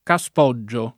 [ ka S p 0JJ o ]